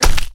Headshot02.wav